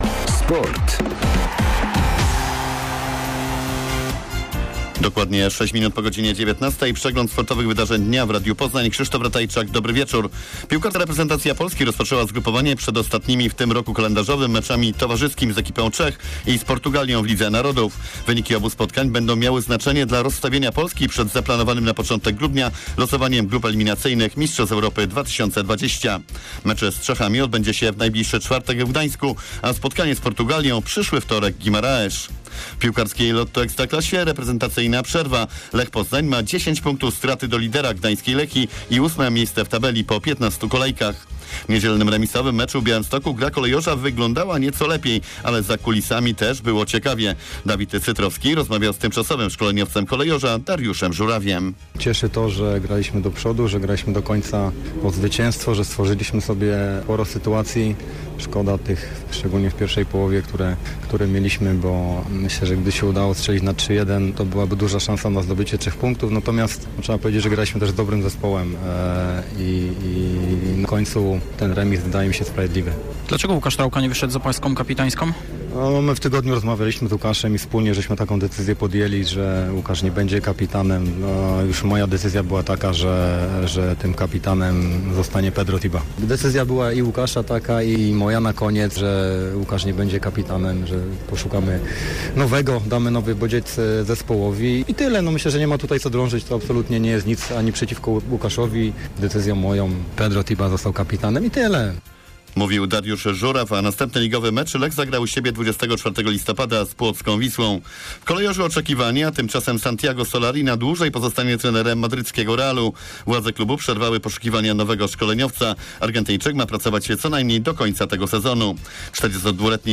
12.11. serwis sportowy godz. 19:05